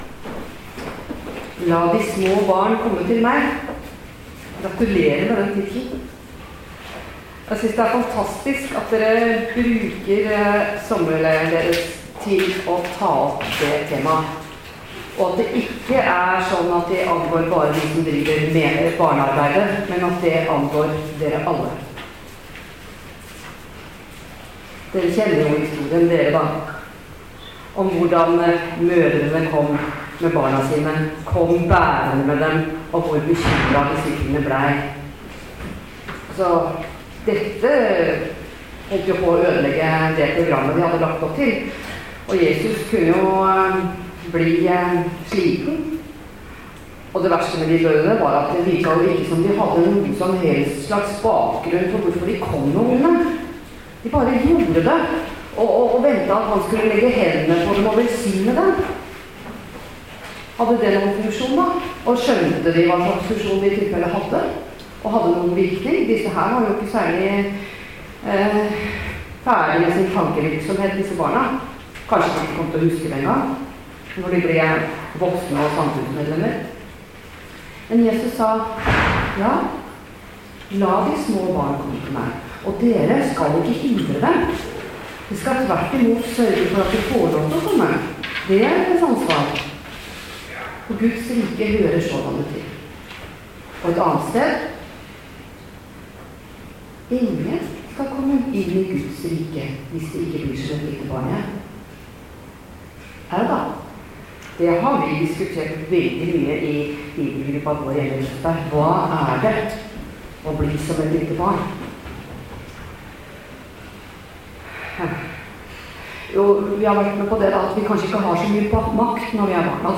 Tale
på FBBs sommerstevne 2016.